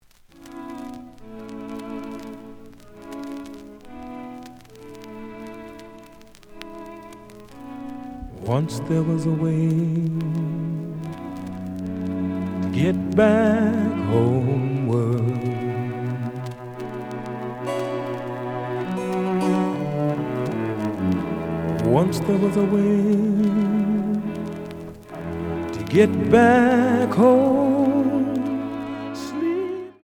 (Stereo)
The audio sample is recorded from the actual item.
●Genre: Jazz Rock / Fusion